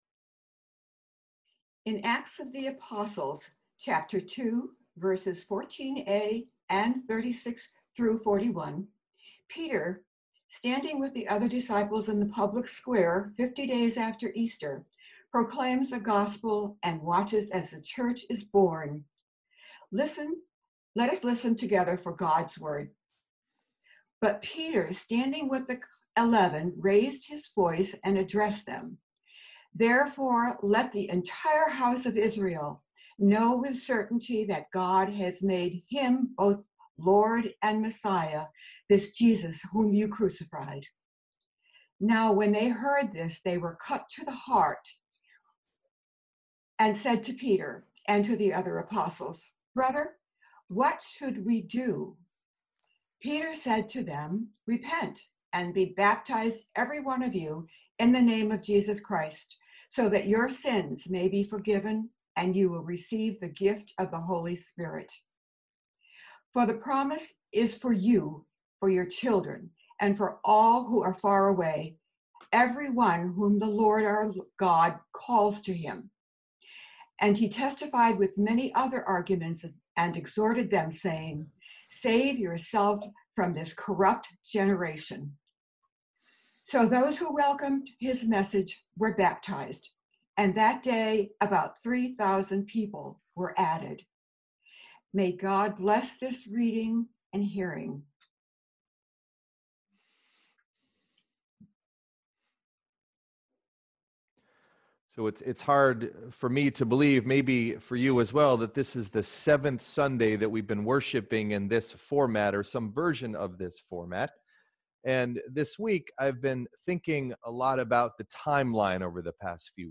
Message Delivered at: Charlotte Congregational Church (UCC)